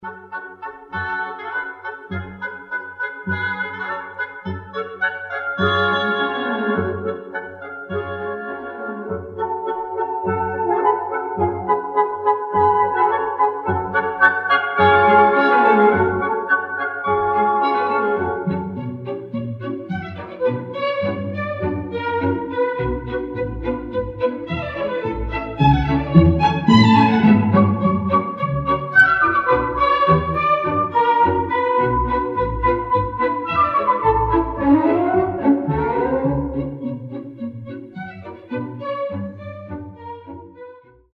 Рингтоны » Классические